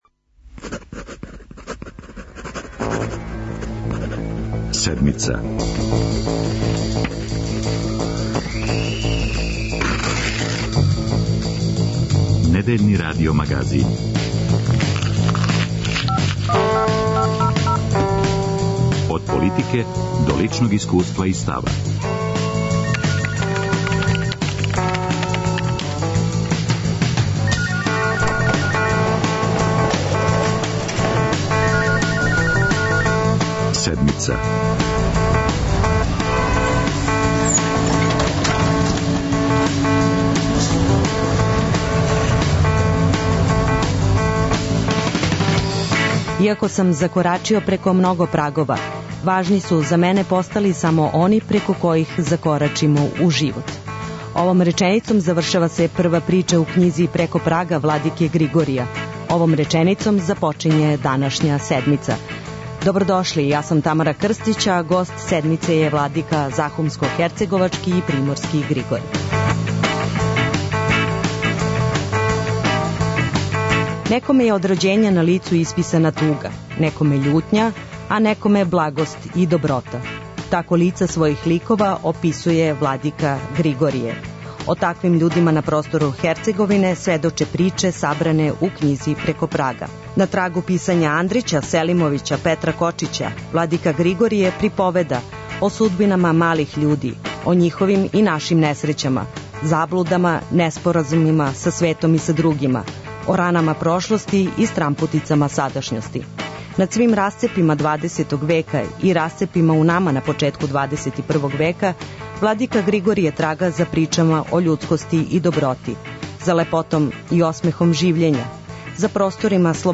'Иако сам закорачио преко много прагова, важни су за мене постали само они преко којих закорачимо у живот'- Речи су владике захумско-херцеговачког и приморског Григорија из књиге прича 'Преко прага'. О тим важним праговима, о животу и литератури, о заборављеним вредностима, о рату и ранама прошлости за Седмицу говори владика Григорије.